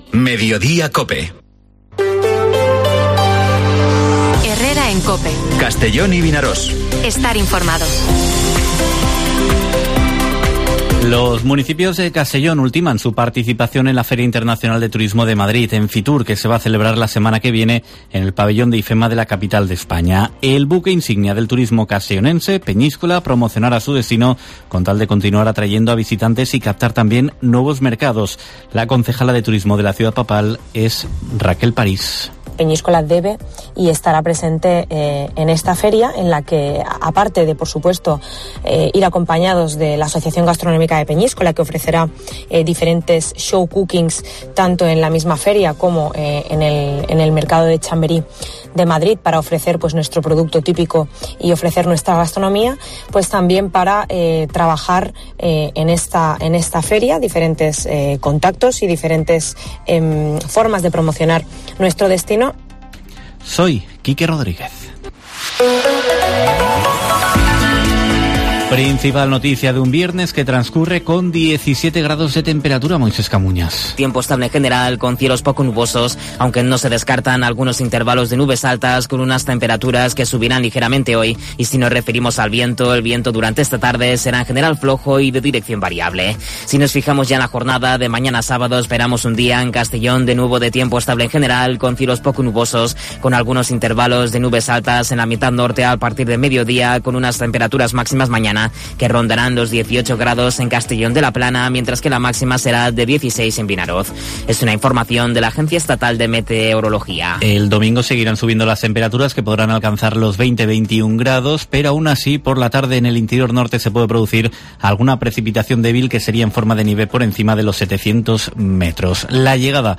Informativo Mediodía COPE en la provincia de Castellón (13/01/2023)